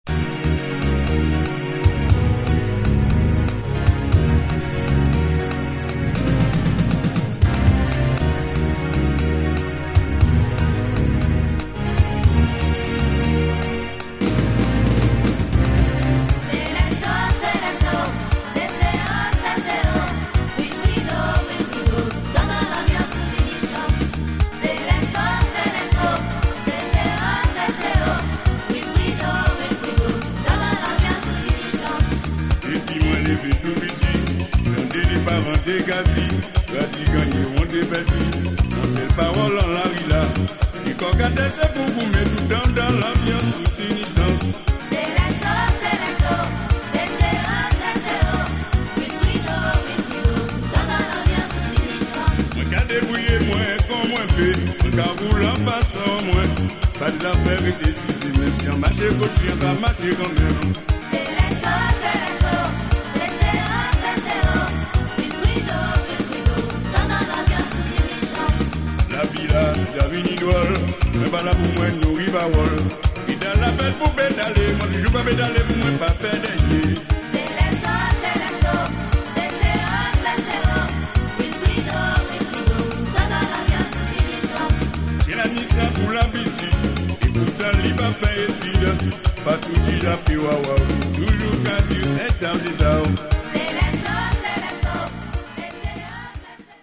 Zouk funk all over !
Caribbean